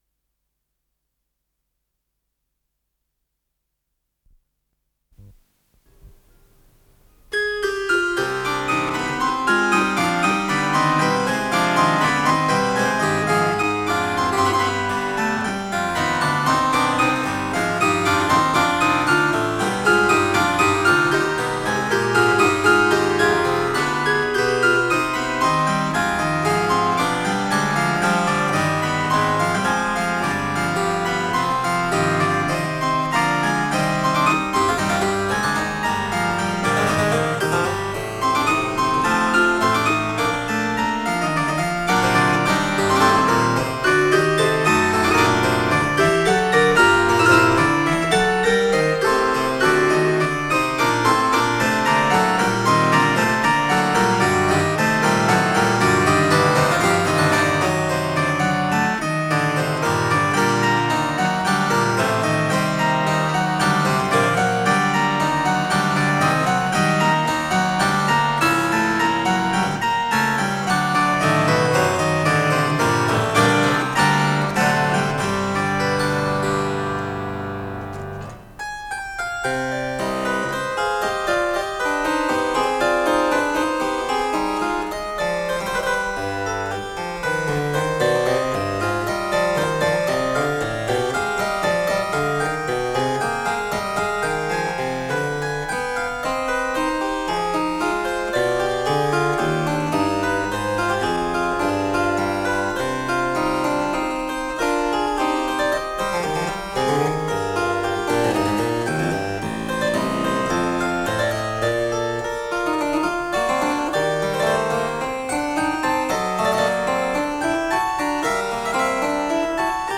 с профессиональной магнитной ленты
ИсполнителиРальф Киркпатрик - клавесин
ВариантДубль моно